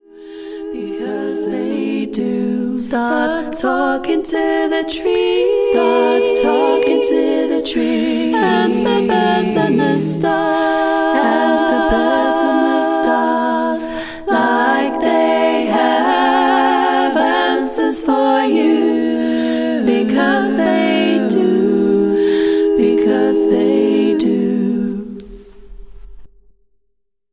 A song for group singing
Parts – 4